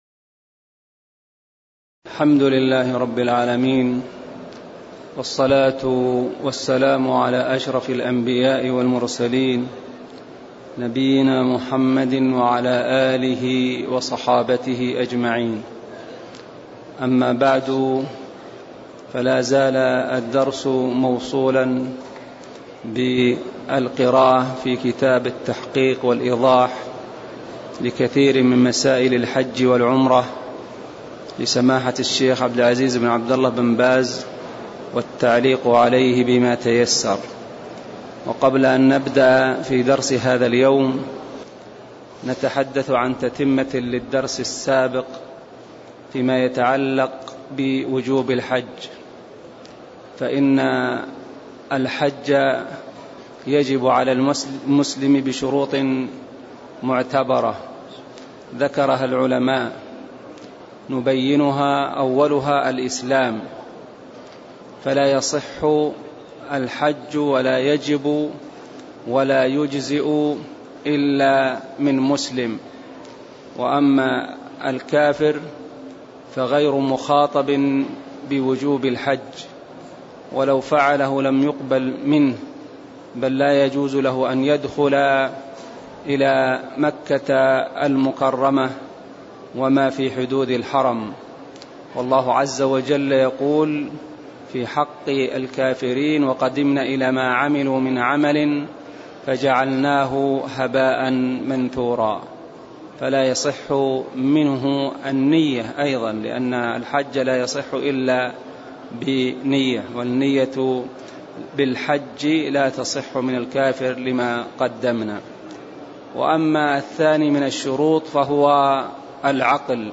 تاريخ النشر ١٥ ذو القعدة ١٤٣٦ هـ المكان: المسجد النبوي الشيخ: فضيلة الشيخ عبدالله بن محمد آل خنين فضيلة الشيخ عبدالله بن محمد آل خنين من قوله: وإذا وصل الميقات (02)